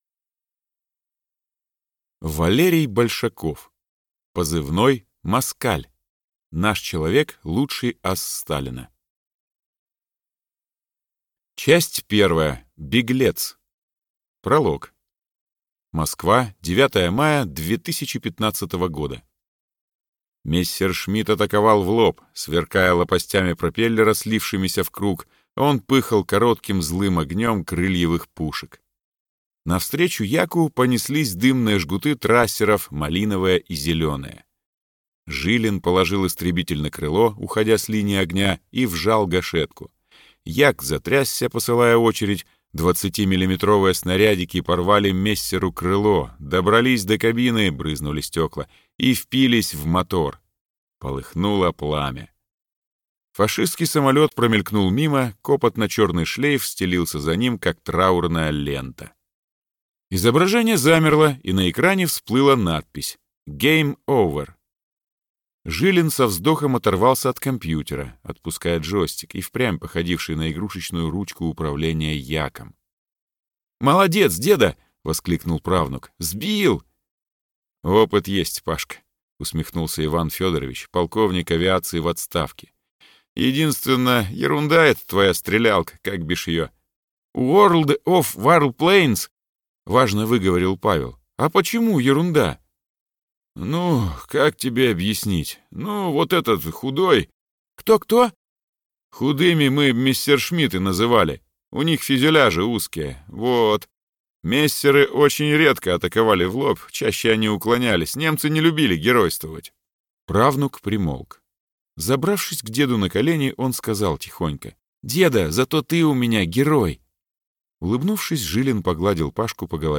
Аудиокнига Позывной: «Москаль». Наш человек – лучший ас Сталина | Библиотека аудиокниг